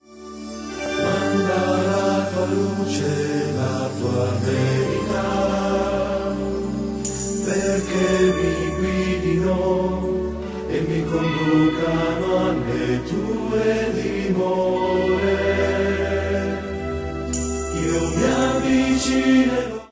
� un �andante�, questo canto dal tono confidente e sereno.